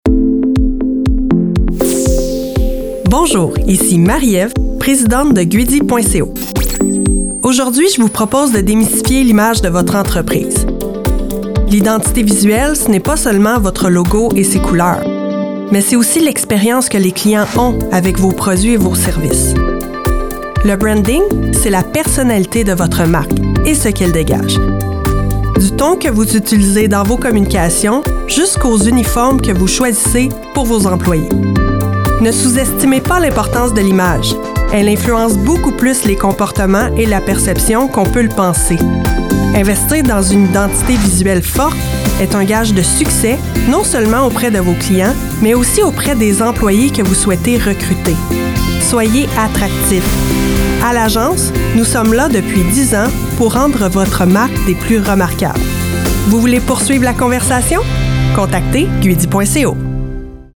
radio vignette